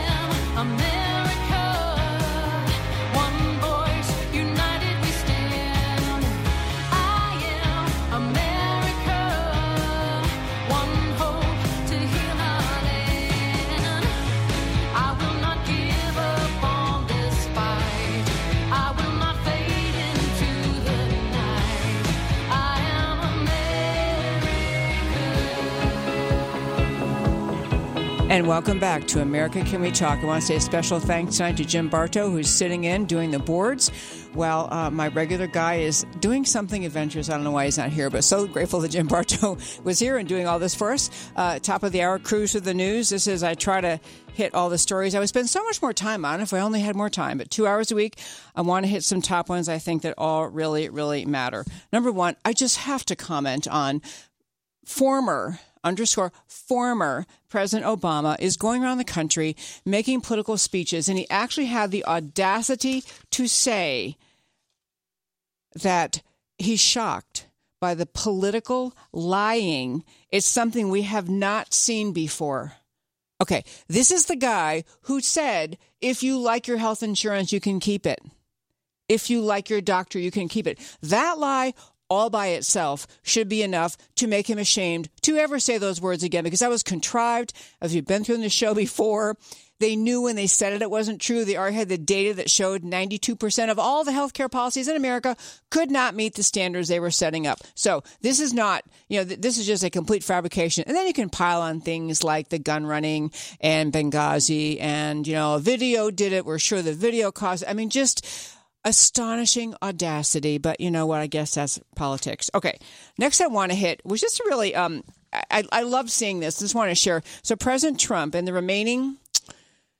Guest interview – Matt Rinaldi, Texas State Representative, District 115